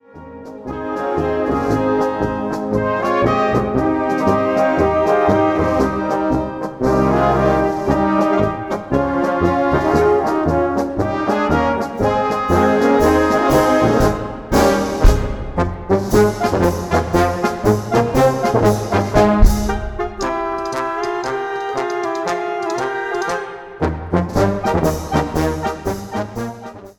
Live-Album zum Jubiläum
Blasmusik
Blasmusik auf höchstem Niveau.